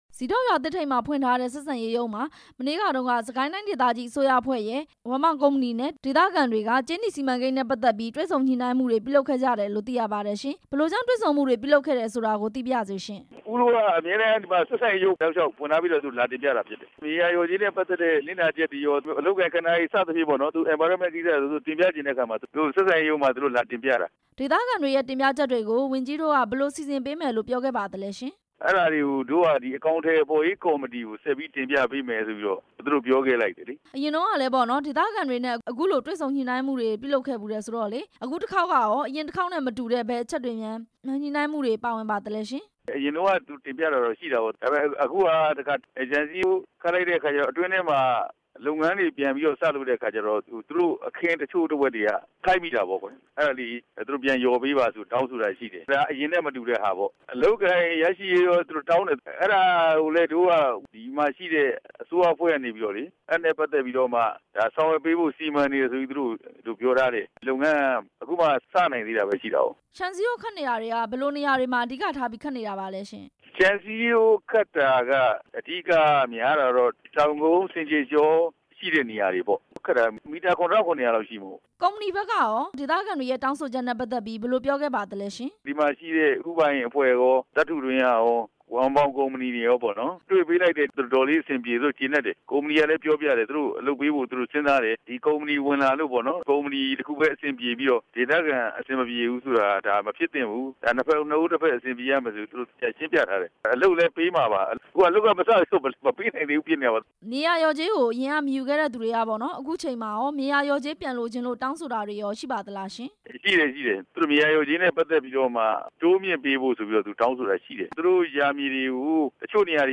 ဗိုလ်မှူးကြီး ကြည်နိုင်နဲ့ မေးမြန်းချက်